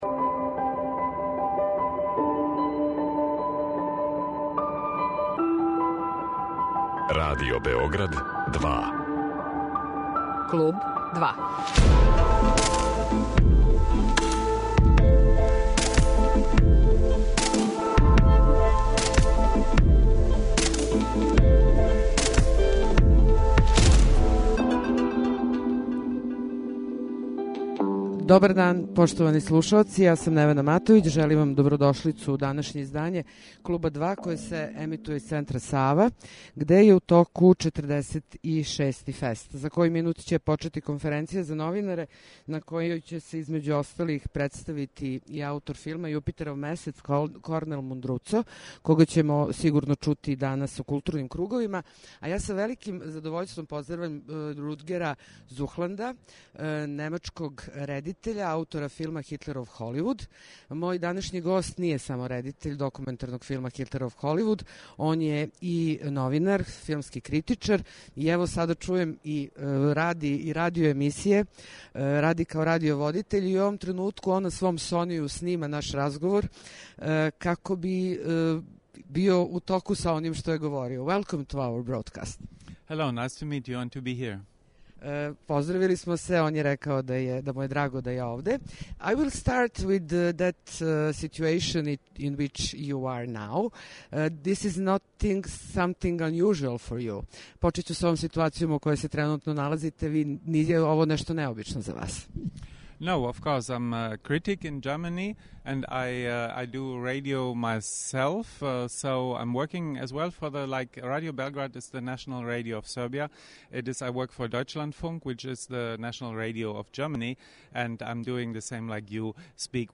који се емитује из Центра Сава, где је у току 46. ФЕСТ